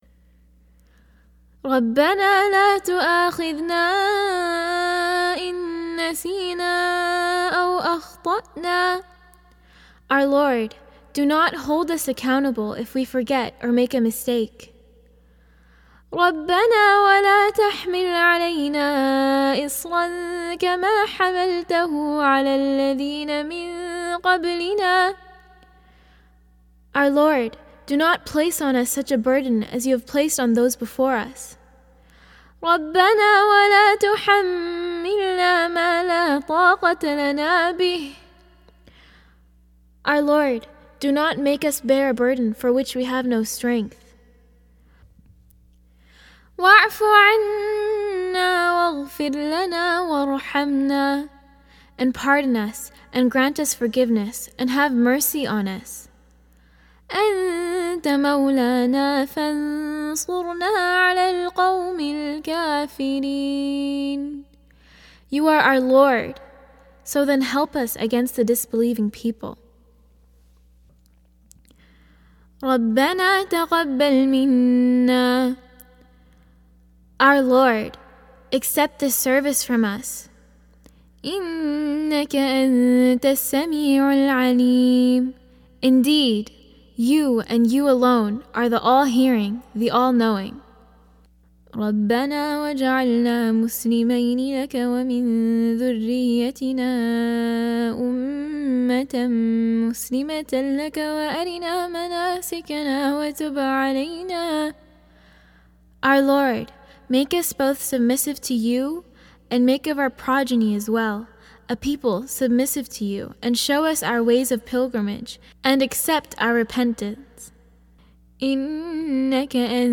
30+ duas from Quran with back to back translation so your child can listen, understand and memorize these beautiful duas - one dua a day in sha Allah.